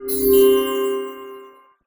vanish_spell_flash_potion_03.wav